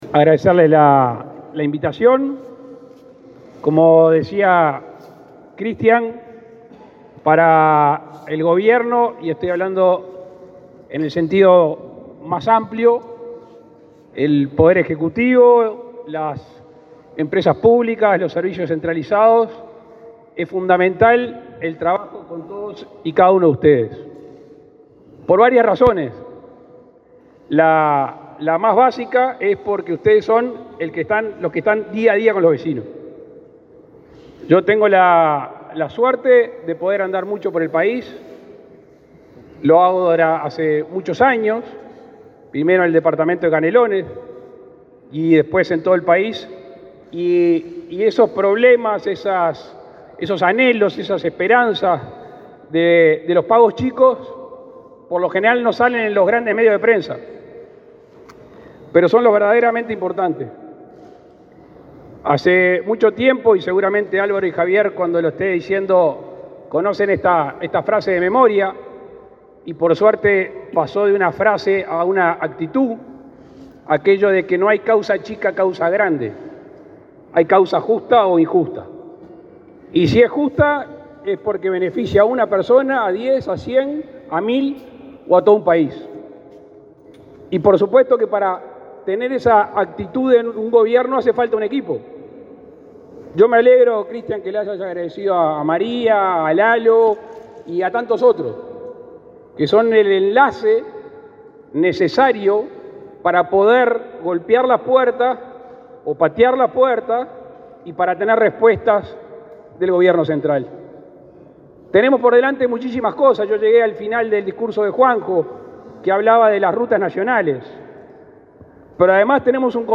Palabras del presidente Luis Lacalle Pou
El presidente Luis Lacalle Pou participó de la XI sesión del Plenario de Municipios de Uruguay, realizado este lunes 31 en el Palacio Legislativo.